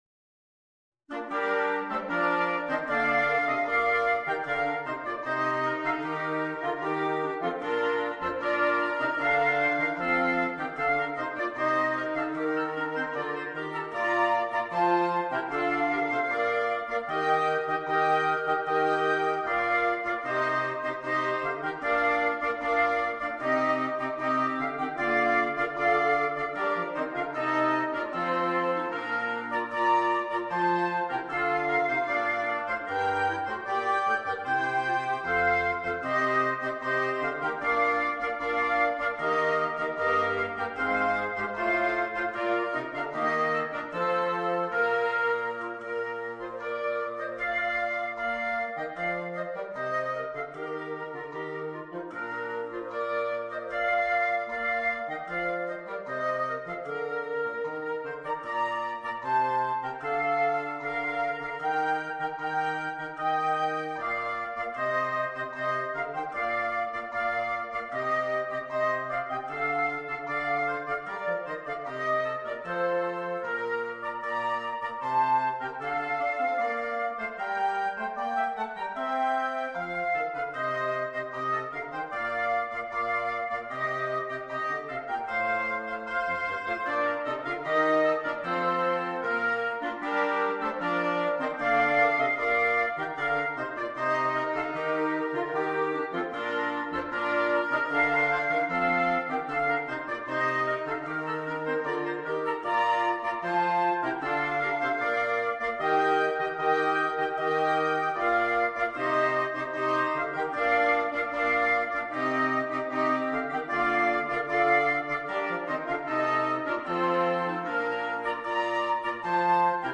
French Christmas Carols on the Instruments